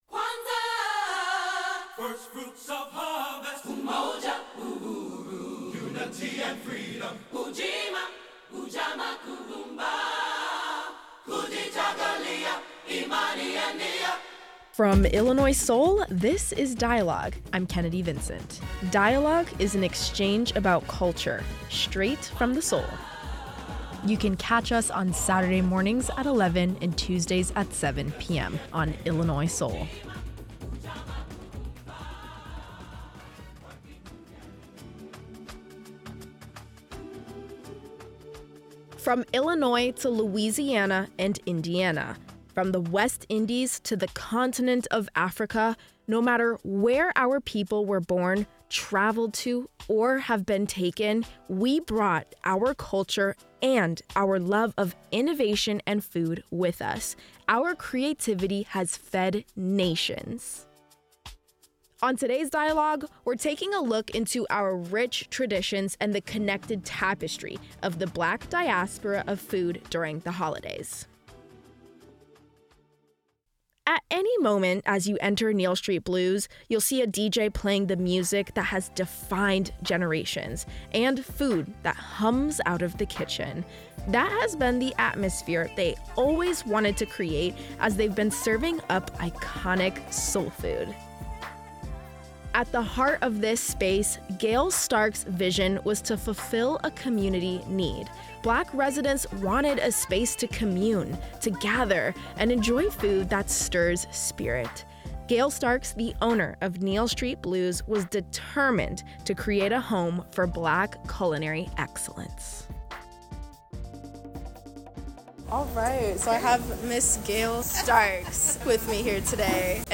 From the West Indies to Illinois, hear from experts on how these traditions have lasted.